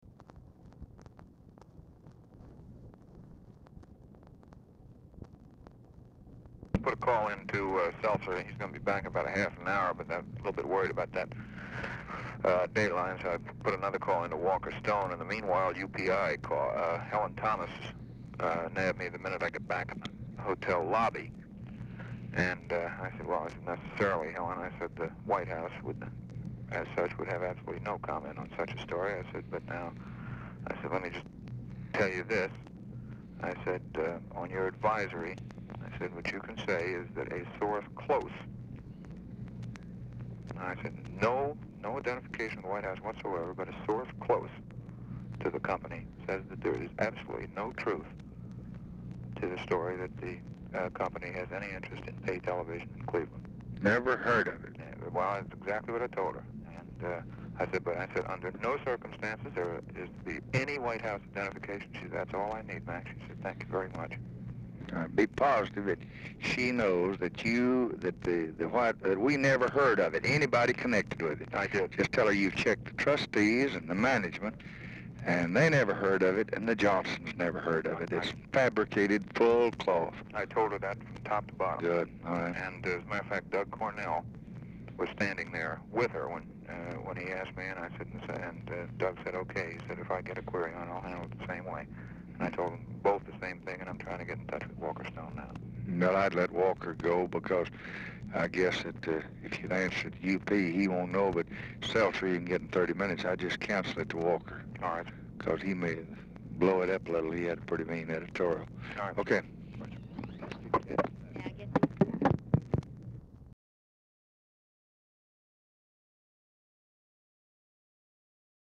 Telephone conversation # 6323, sound recording, LBJ and MAC KILDUFF, 11/11/1964, 7:35PM
RECORDING STARTS AFTER CONVERSATION HAS BEGUN
Format Dictation belt
Location Of Speaker 1 LBJ Ranch, near Stonewall, Texas